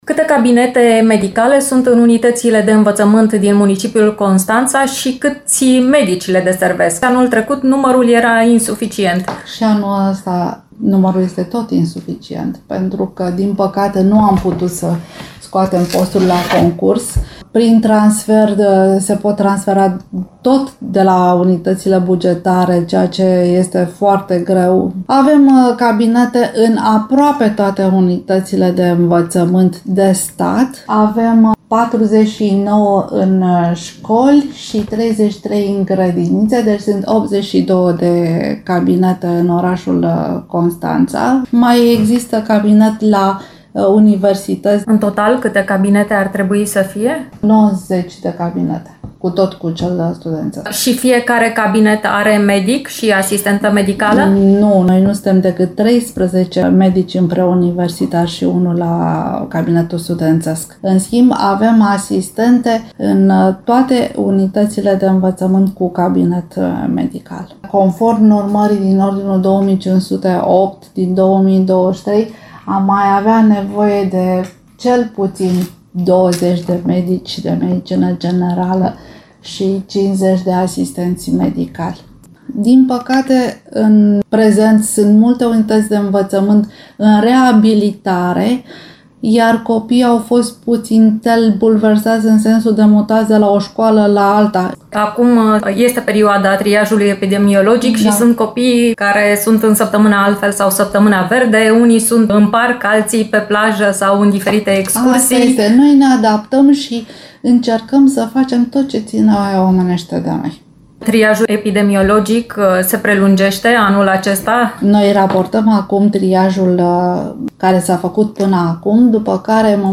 Detalii, în interviul